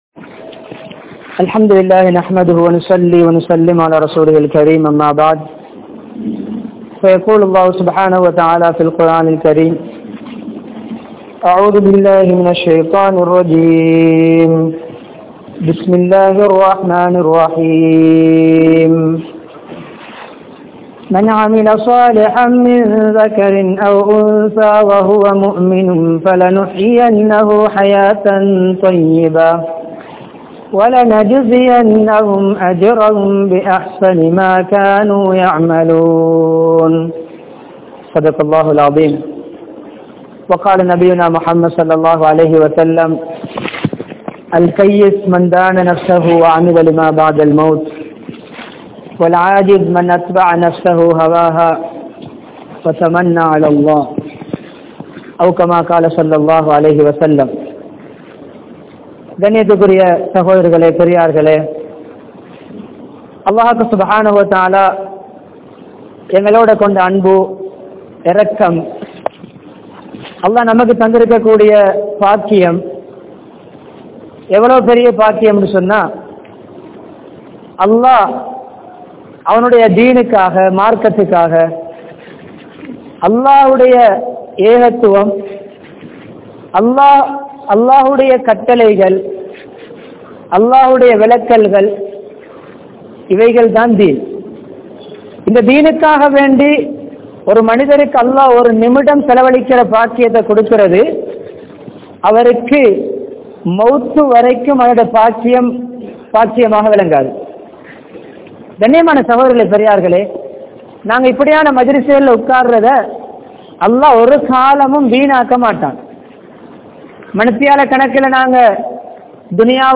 Nimmathi Veanduma? (நிம்மதி வேண்டுமா?) | Audio Bayans | All Ceylon Muslim Youth Community | Addalaichenai
Nooraniya Jumua Masjidh